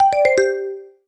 match-join.wav